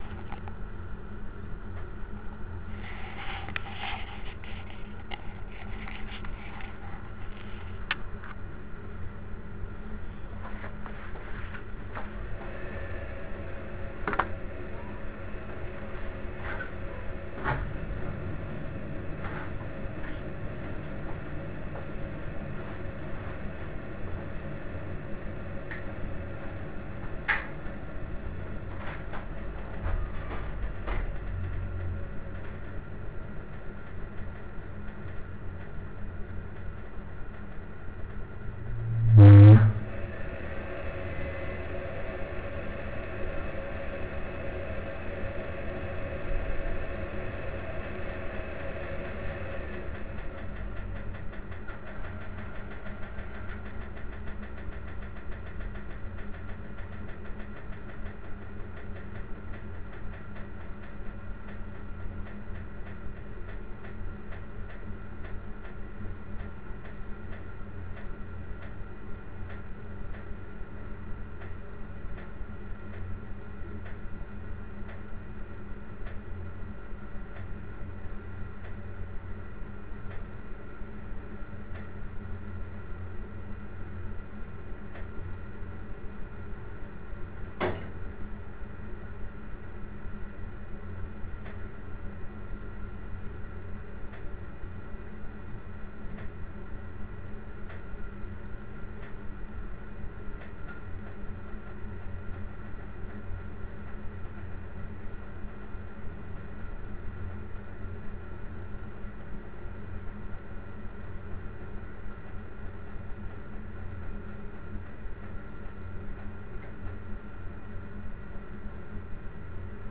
Bruit chaudière à condensation Atlantic Franco Belge Idra
Depuis déjà quelques temps à chaque sollicitation de la chaudière, notamment aux périodes directement postérieures au démarrage horaires, programmés (matin et soir), celle-ci émet un bruit intempestif de type ronflement (ci-joint un fichier audio).
Les vibrations de l'ensemble de chauffe sont amplifiées par les parties des carters métalliques pendant des périodes d'environ 30 secondes.
bruit-chaudiere-idra-condens-minute-6.wav